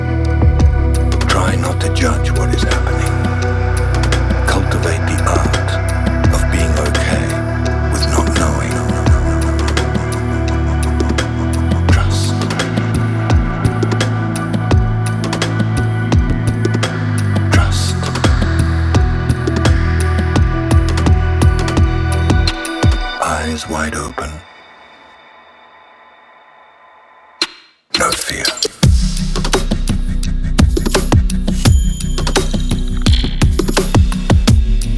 Жанр: Электроника
Downtempo, Electronic, Electronica